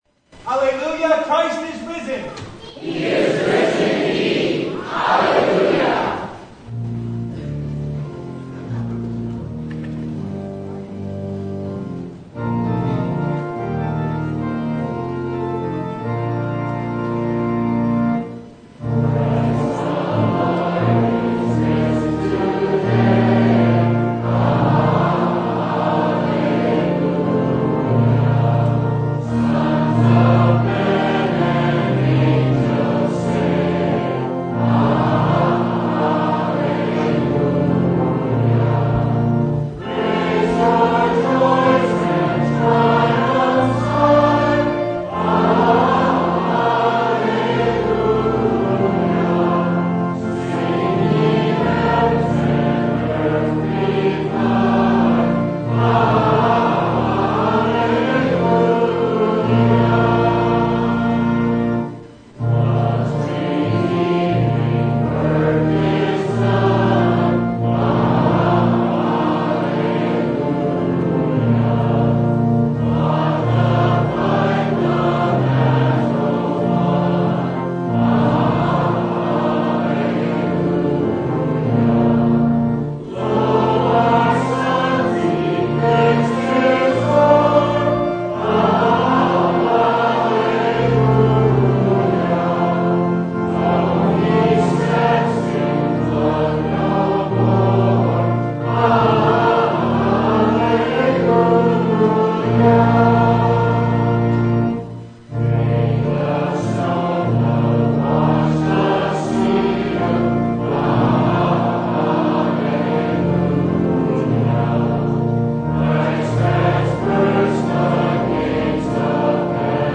Full Service